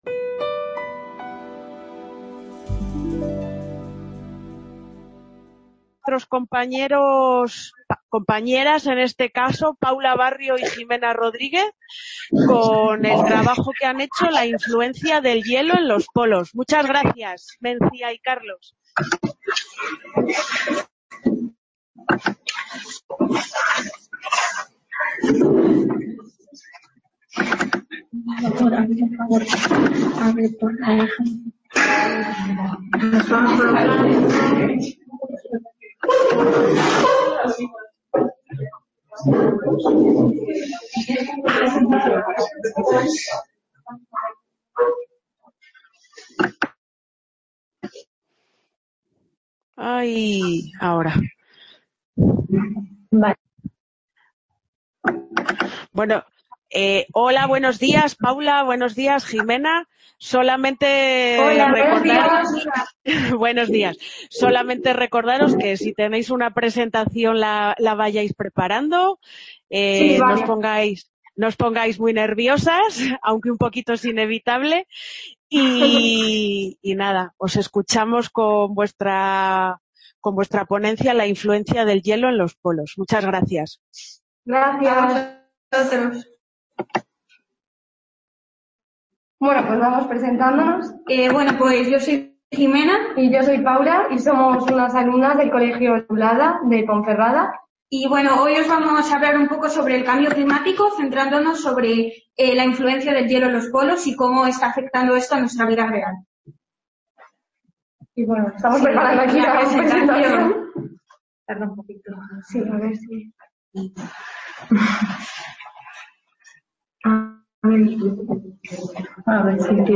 Description Congreso organizado por La Fábrica de Luz. Museo de la Energía junto con la ULE, la UNED y en colaboración con FECYT que se desarrolla en 3 salas CA Ponferrada - 2 Edición Congreso de Jóvenes Expertos.